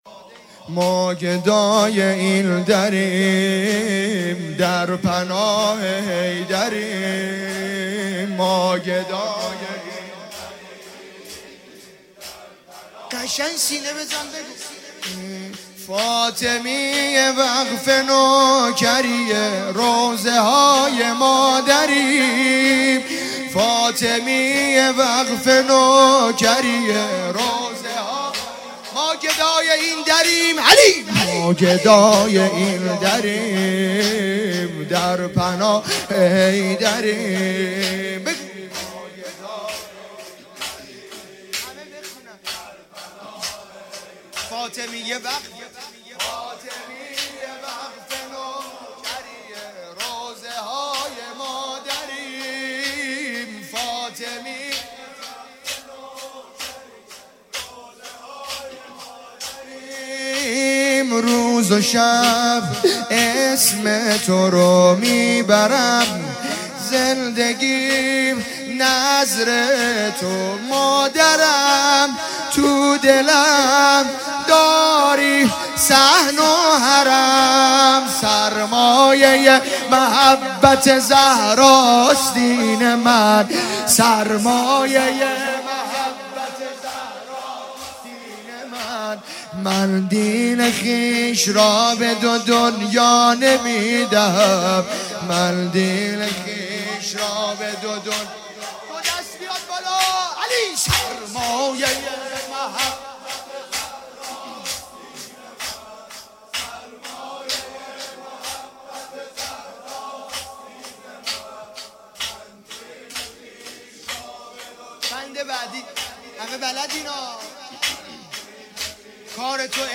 اقامه عزای روضه حضرت صدیقه شهیده علیها السلام _ شب اول